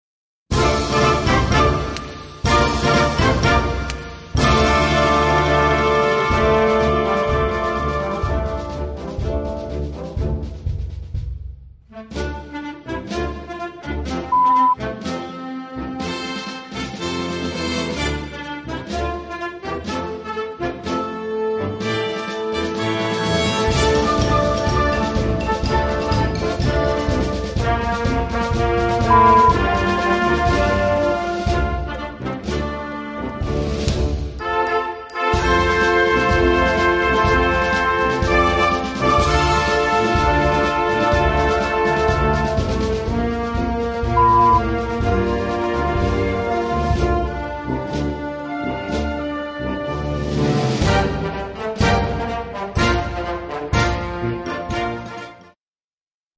Gattung: Blasmusik für Jugendkapelle
Besetzung: Blasorchester